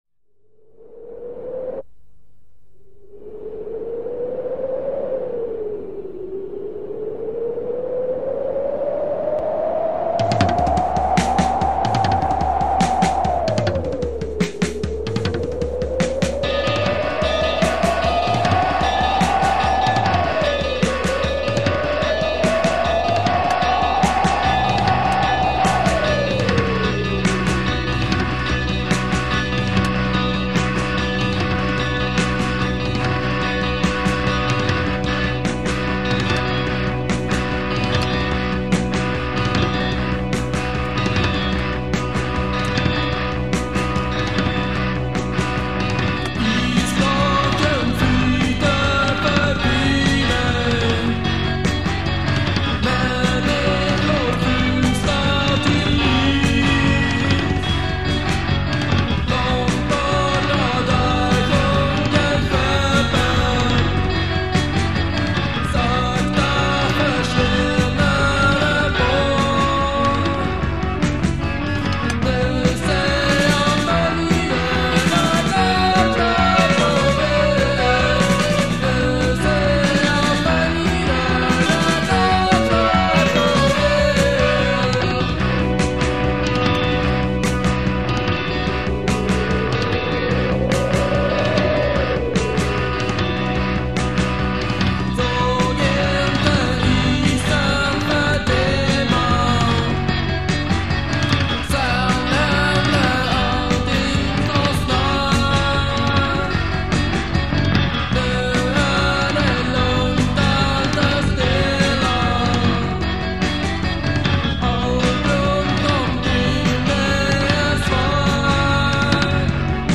Guitar, Voice
Drums
Bass
Organ, Synthesizer